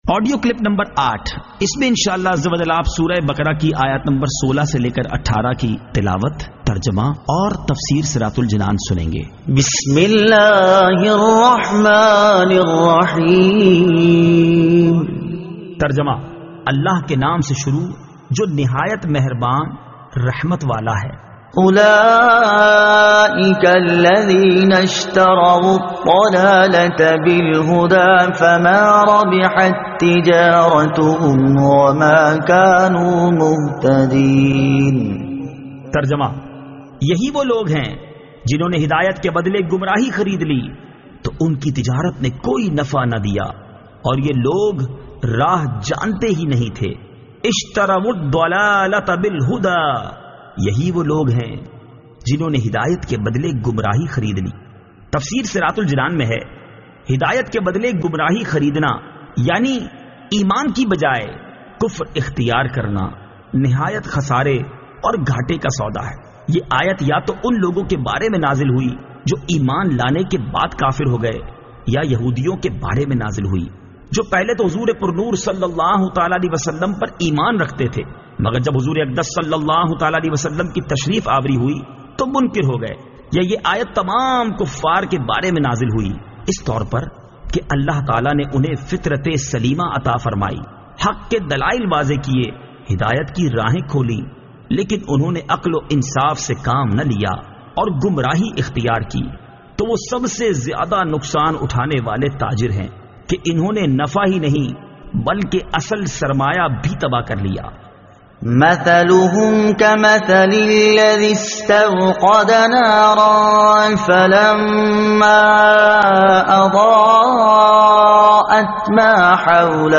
Surah Al-Baqara Ayat 16 To 18 Tilawat , Tarjuma , Tafseer